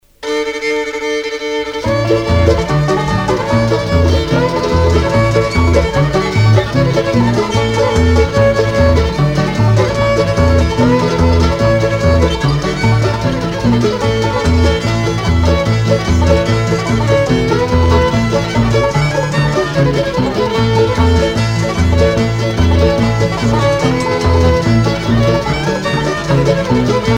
danse : reel
Pièce musicale éditée